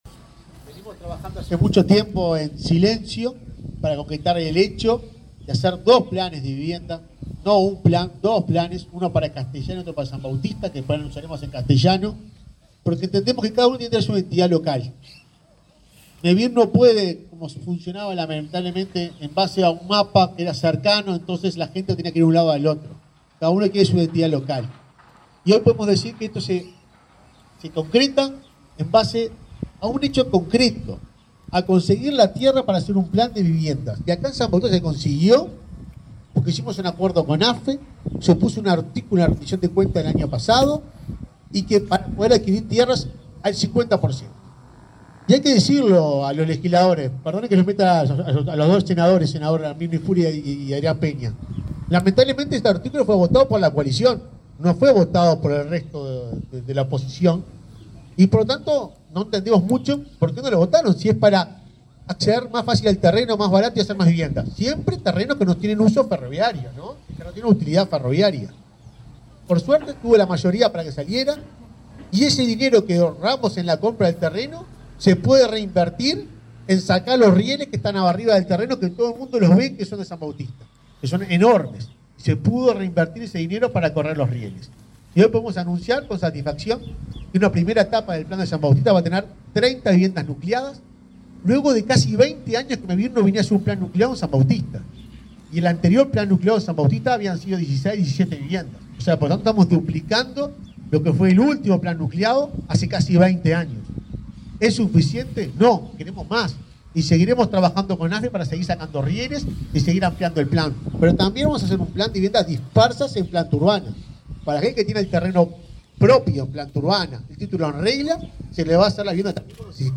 Palabras de autoridades en acto de Mevir
El presidente de Mevir, Juan Pablo Delgado, y el subsecretario de Vivienda, Tabaré Hackenbruch, participaron en un acto en el que se anunció un nuevo